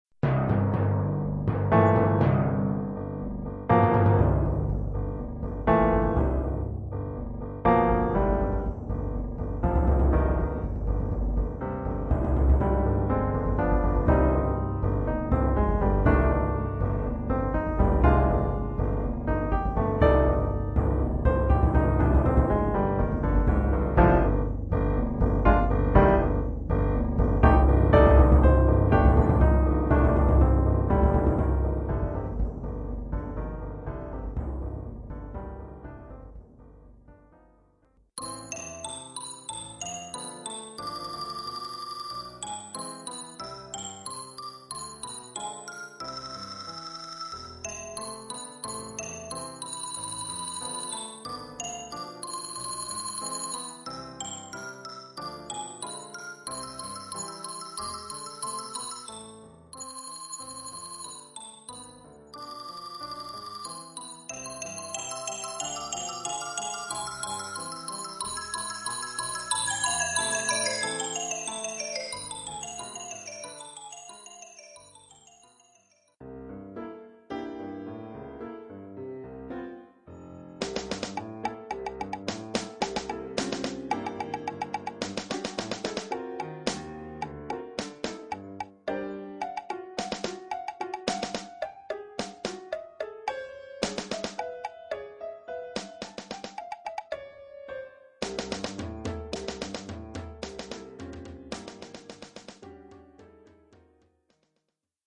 Oeuvre en trois mouvements,
Deux timbales.
Xylophone.
Caisse claire, tom basse, cymbale suspendue, 2 wood-blocks.